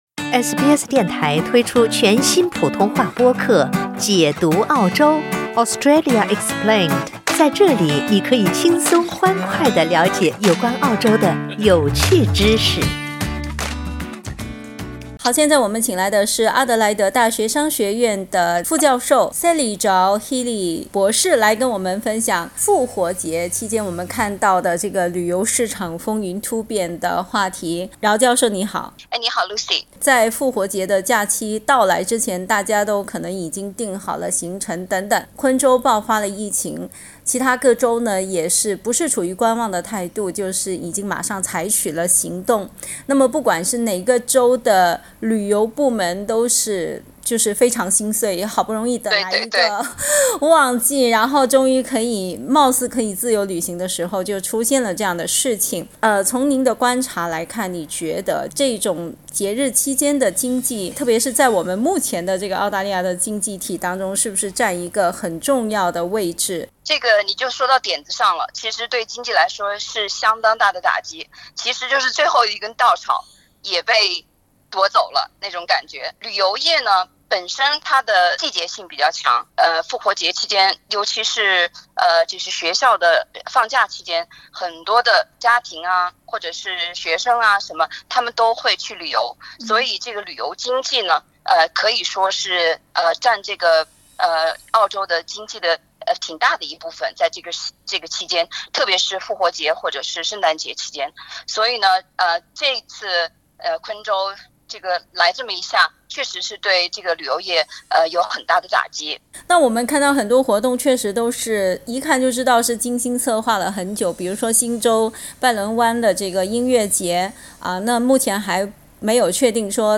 （请听采访。本节目为嘉宾观点，不代表本台立场。）